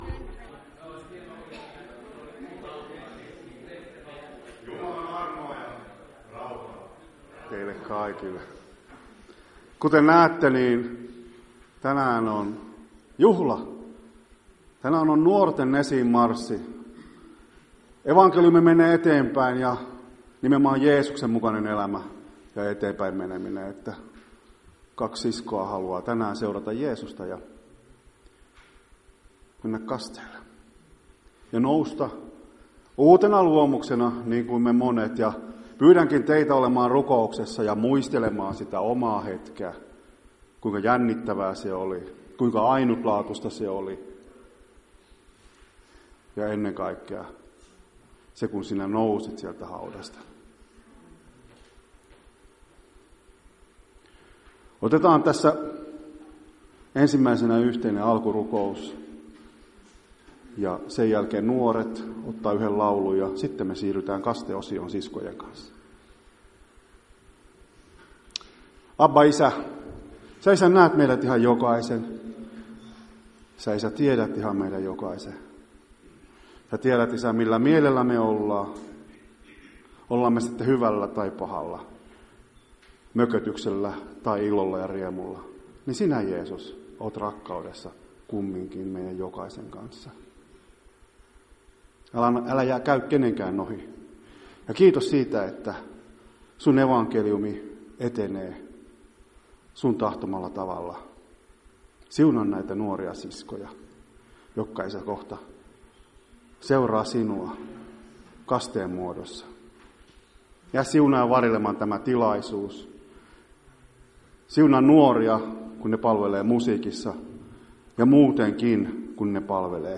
Päiväkokous 19.1.2025